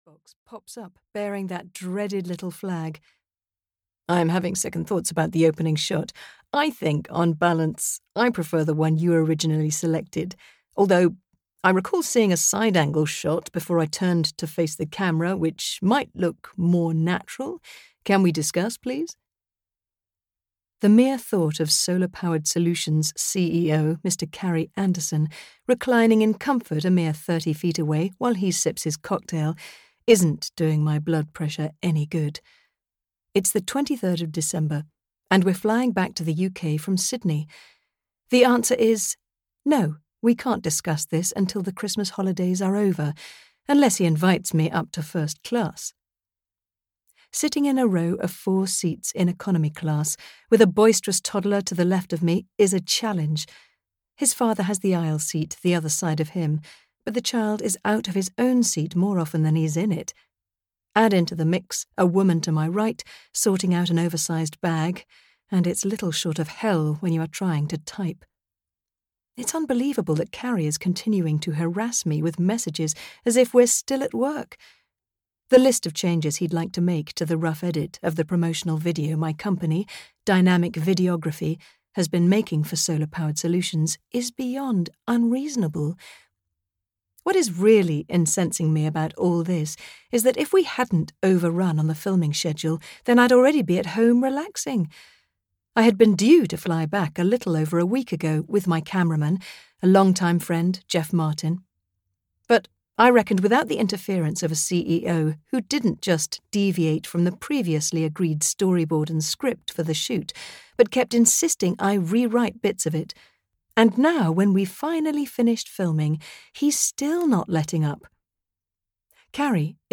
Magic Under the Mistletoe (EN) audiokniha
Ukázka z knihy